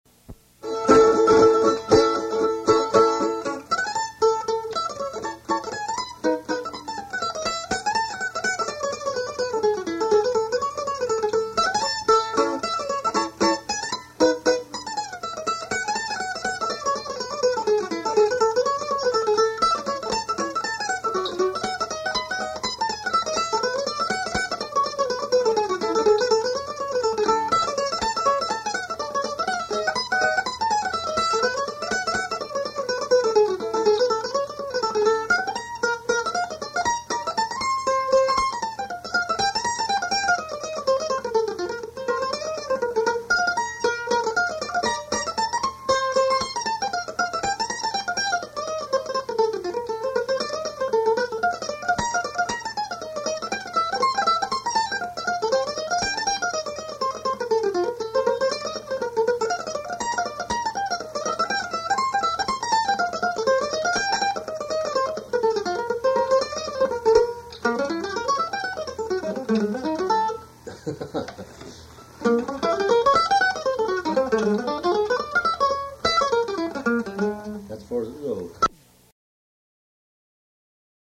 Mandolin